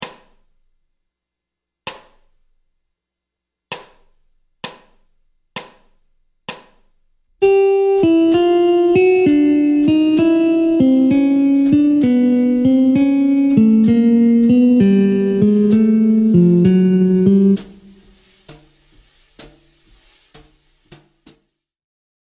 Gamme Majeure ( IV – mode Lydien)
Descente de gamme
Gamme-bop-desc-Pos-42-C-Lydien.mp3